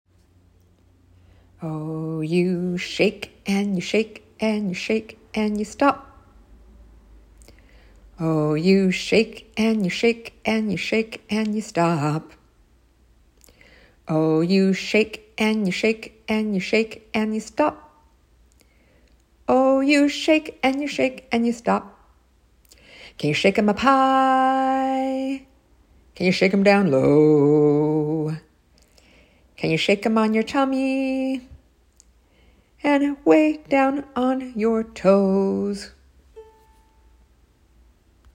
Shaker Songs